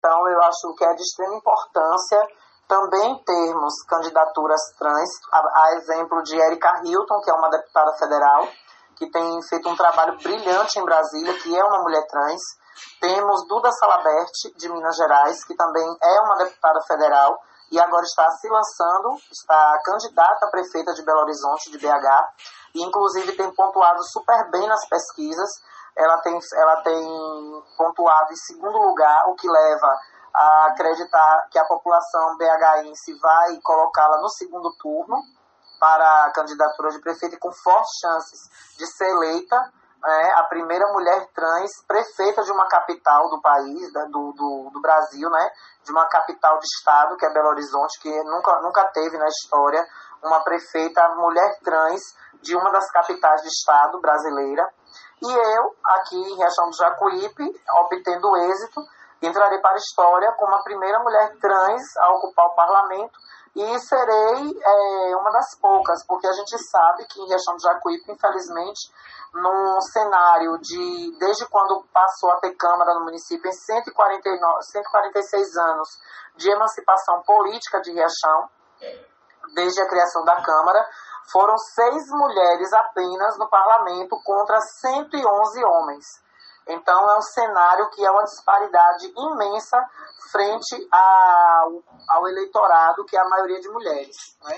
Ela conversou com a reportagem do CN e destacamos alguns pontos de entrevista.